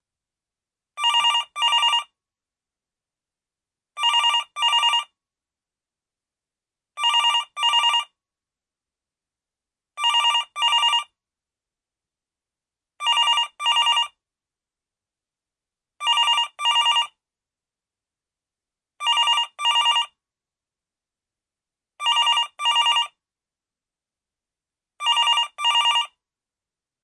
铃声 " 电话铃声
描述：MP3版本的英国座机电话铃声的可循环样本。
标签： 铃声 现场记录 振铃 英国 英国 电话 手机
声道立体声